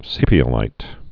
(sēpē-ə-līt)